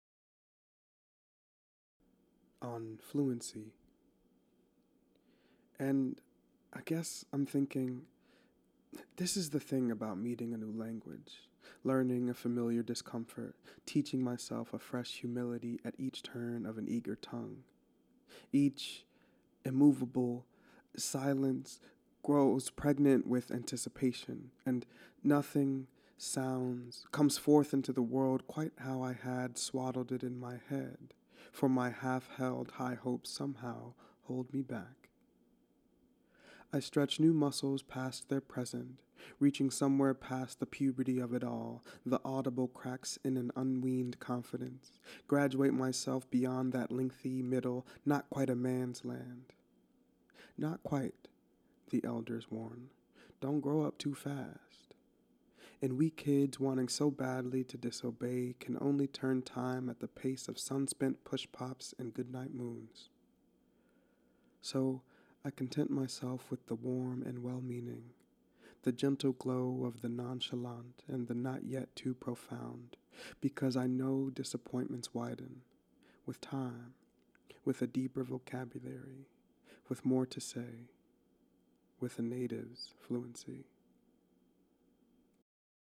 reading on fluency